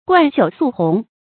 貫朽粟紅 注音： ㄍㄨㄢˋ ㄒㄧㄨˇ ㄙㄨˋ ㄏㄨㄥˊ 讀音讀法： 意思解釋： 見「貫朽粟陳」。